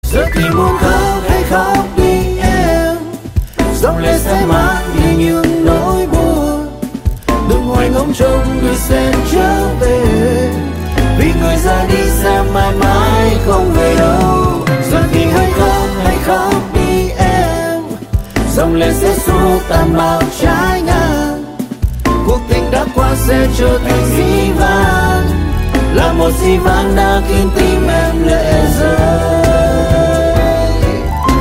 Nhạc Chuông Nhạc Trẻ